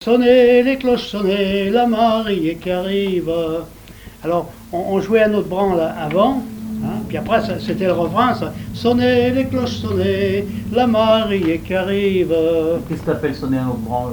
Genre énumérative
Répertoire provenant de La Chapelle-Hermier
Pièce musicale inédite